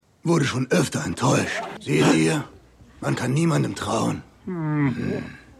Synchronstudio: Iyuno Germany GmbH